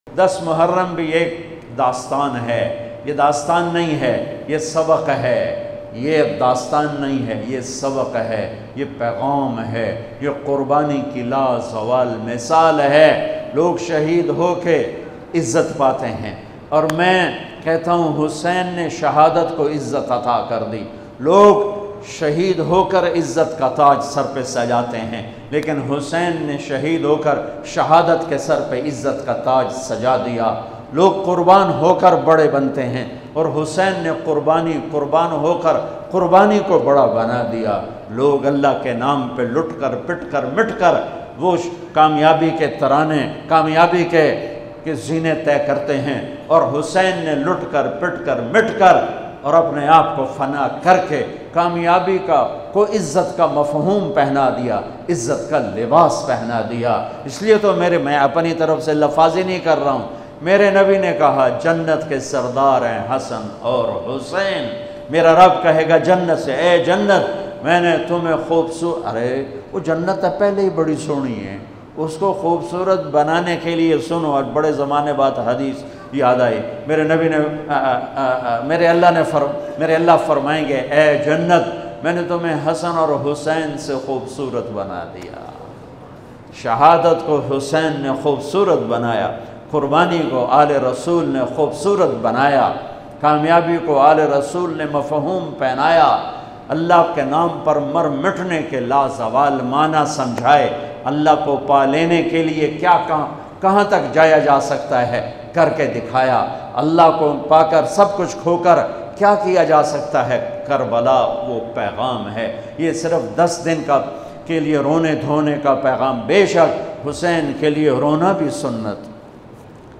10 Muharram Molana Tariq Jameel Latest Bayan MP3 Download